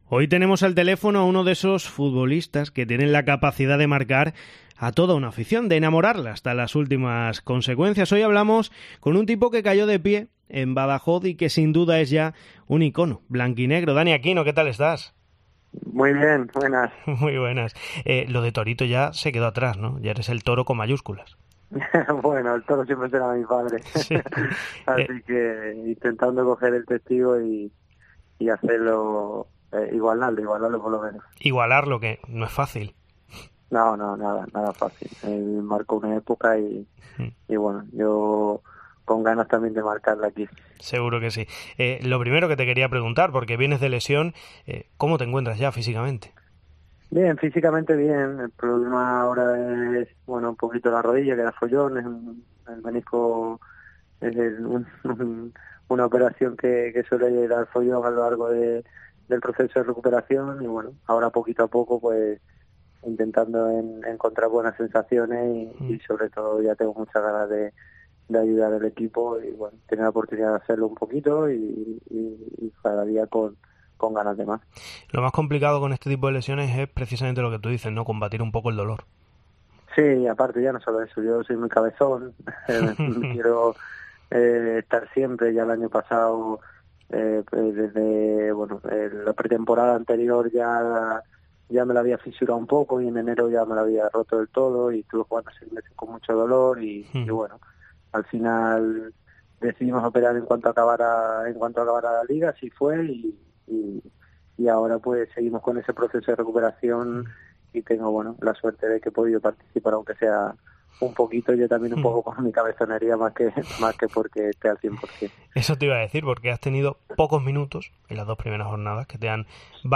AUDIO: El futbolista ha pasado hoy por los micrófonos de Cope Extremadura, donde ha charlado sobre la actualidad del club blanquinegro
ENTREVISTA